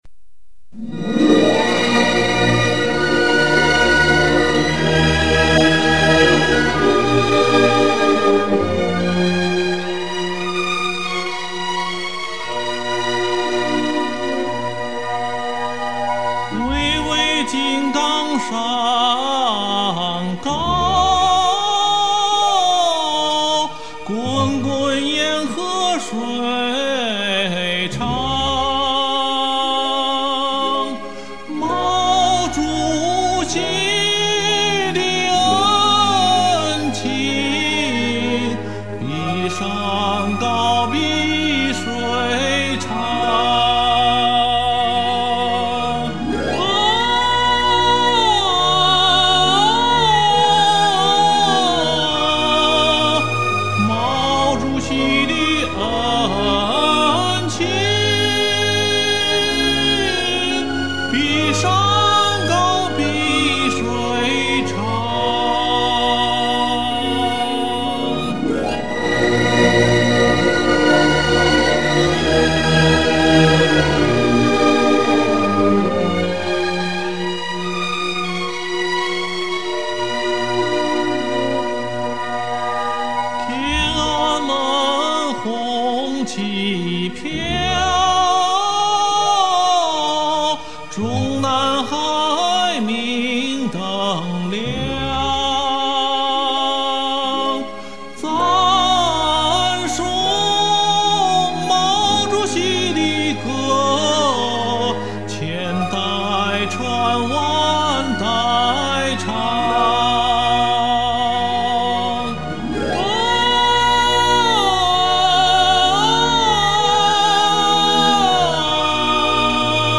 唱的不好，总比没有强啊，呵呵。
主要是调太高，唱起来咬字很不容易，也容易唱走调。）